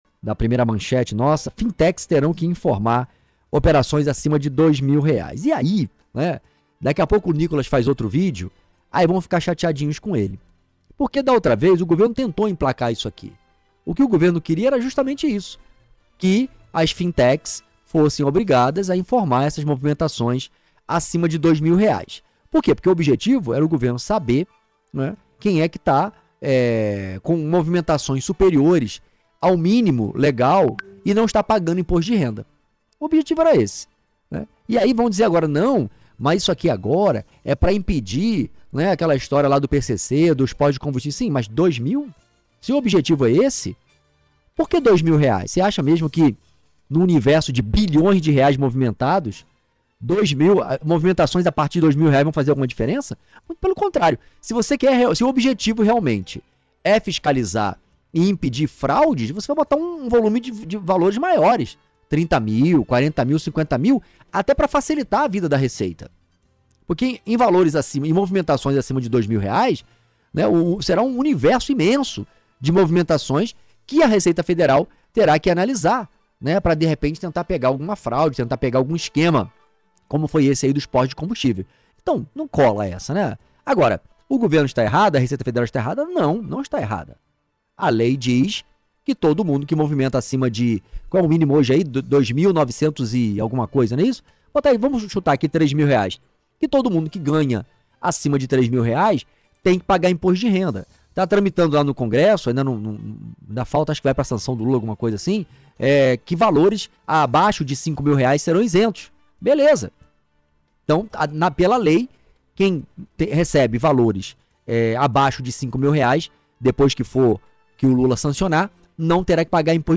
Contexto nacional